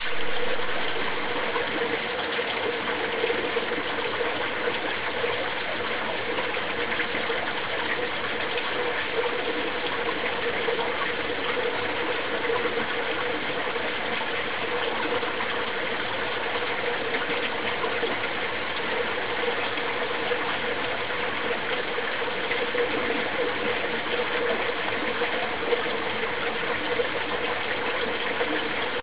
Alpine Brook
alpinebrook01.ogg